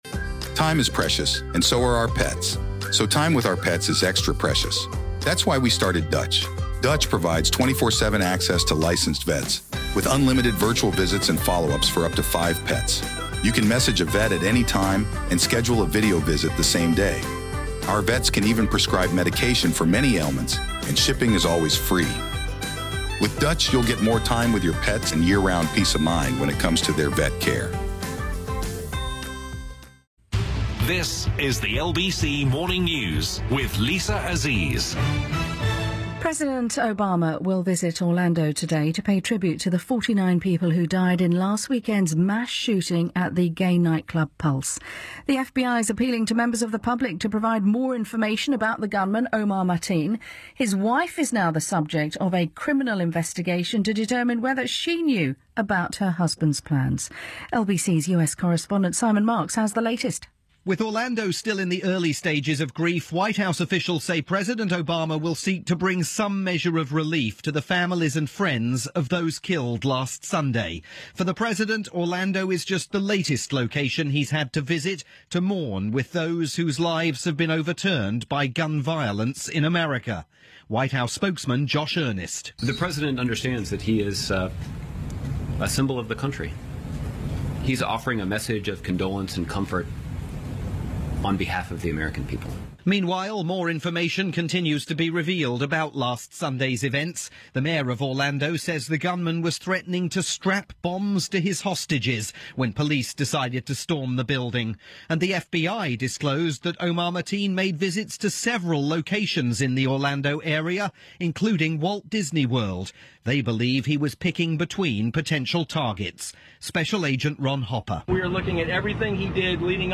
Via the LBC Morning News.